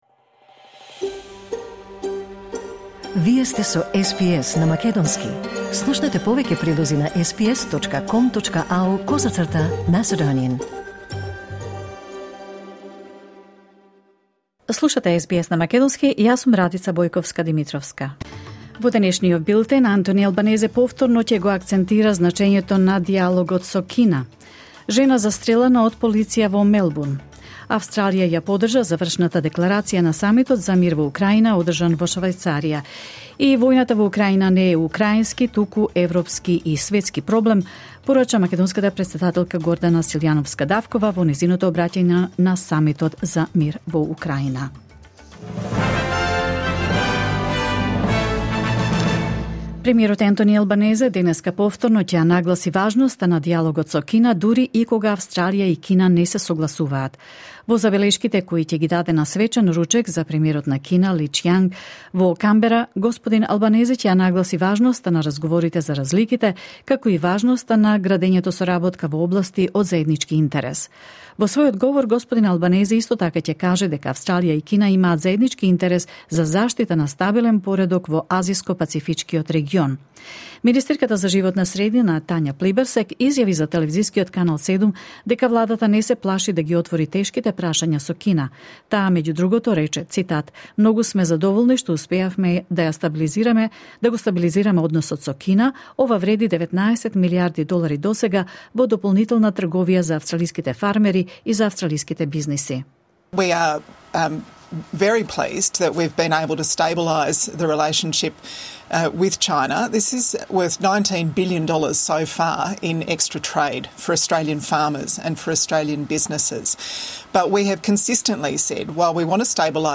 SBS News in Macedonian 17 June 2024